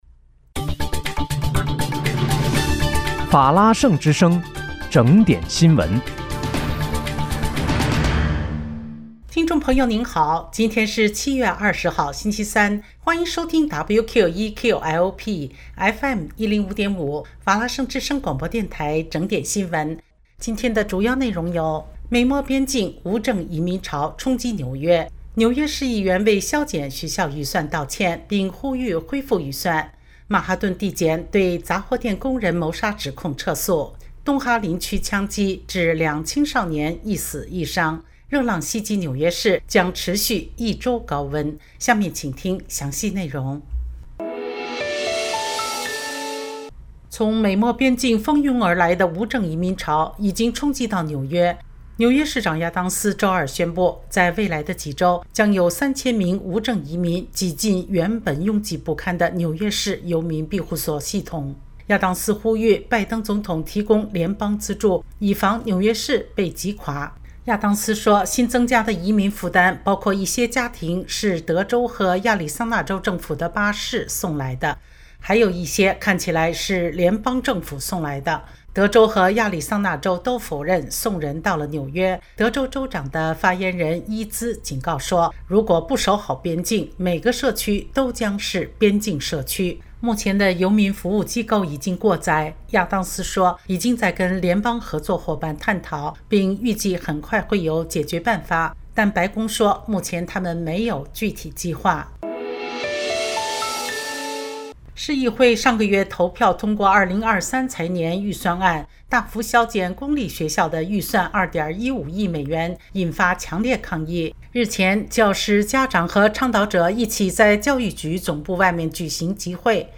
7月20日（星期三）纽约整点新闻
今天是7月20号，星期三，欢迎收听WQEQ-LP FM105.5法拉盛之声广播电台整点新闻。